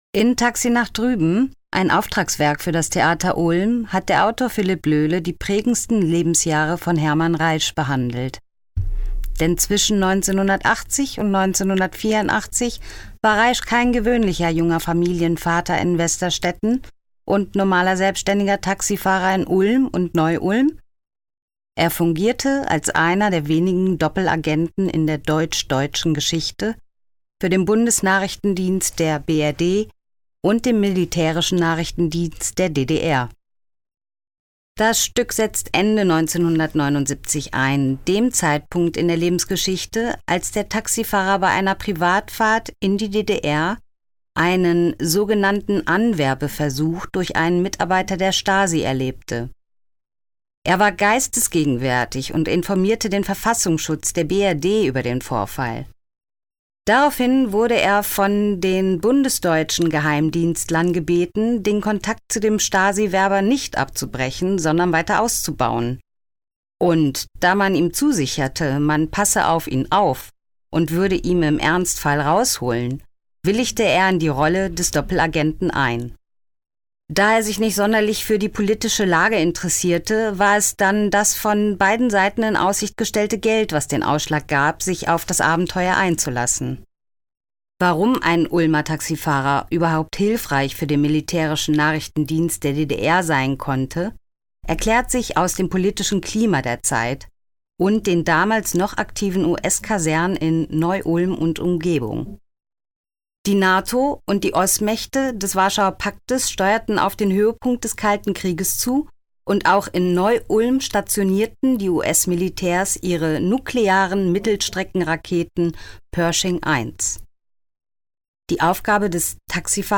Höreinführung
hoereinfuehrung_Taxi_nach_drueben.mp3